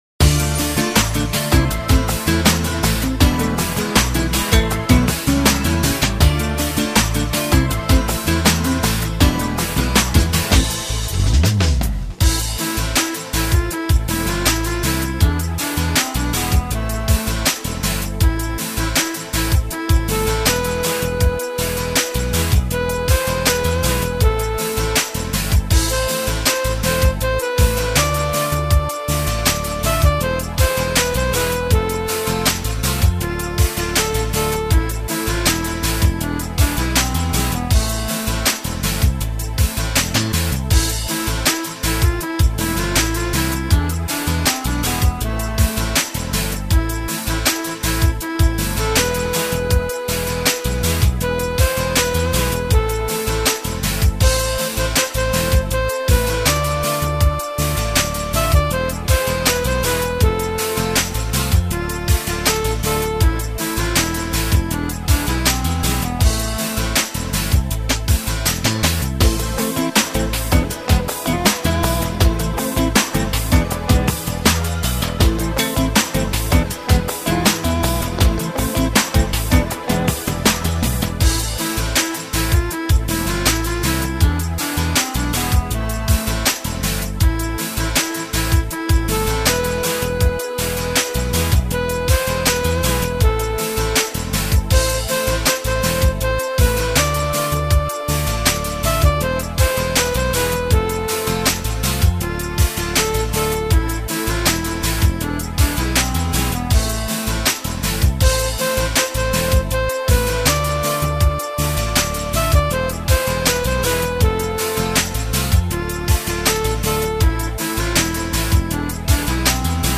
Главная » Файлы » Минусовки » минусы Қазақша